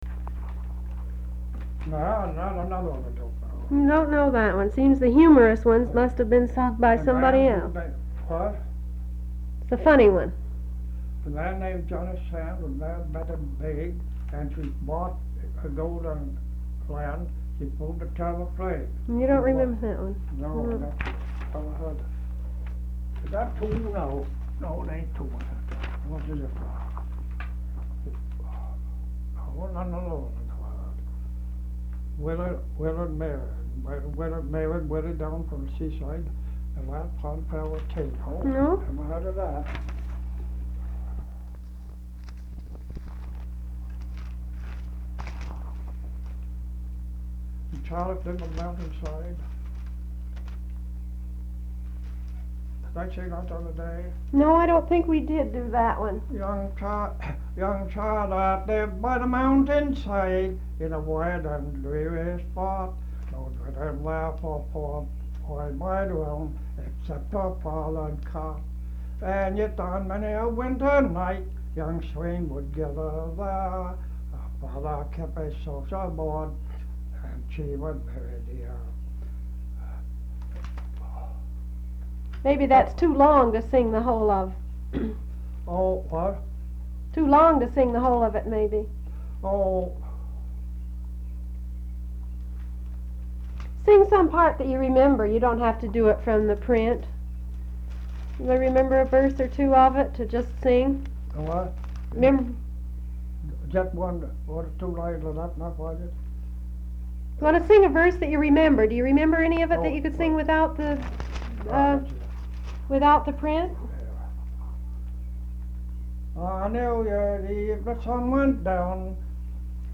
Folk songs, English--Vermont
sound tape reel (analog)
Marlboro, Vermont